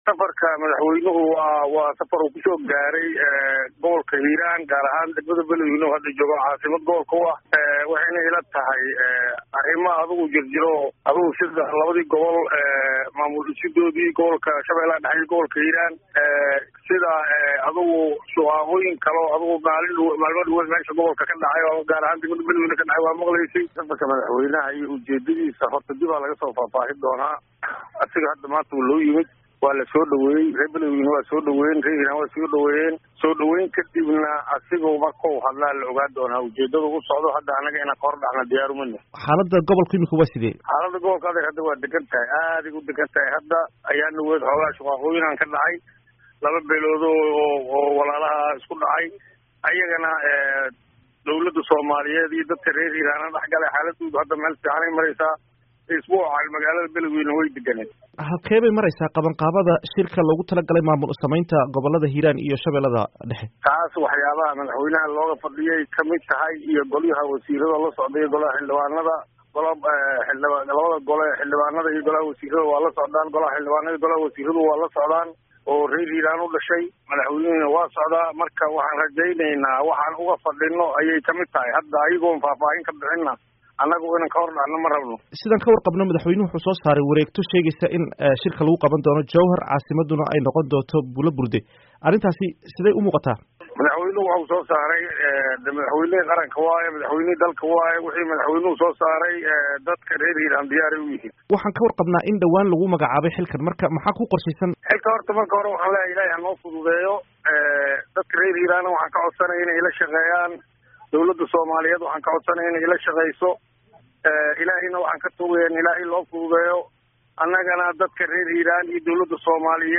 Wareysi: Yuusuf Axmed Hagar